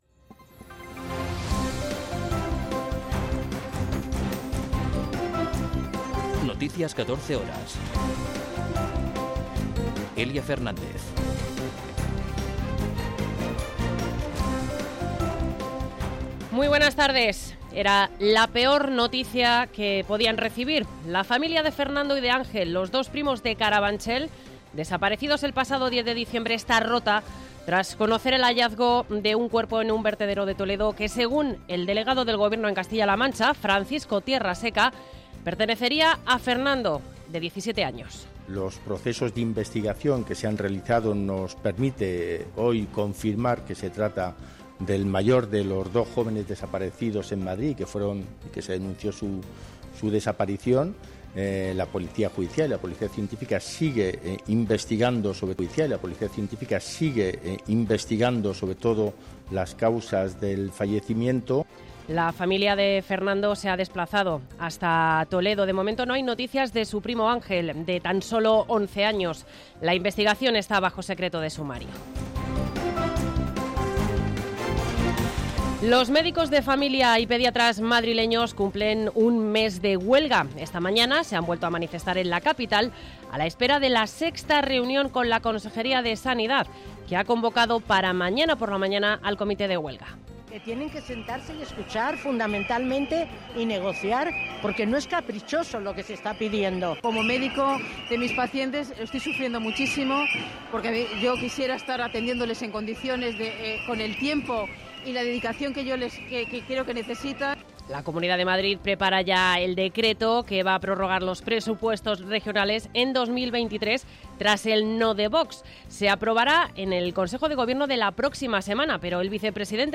Noticias 14 horas 21.12.2022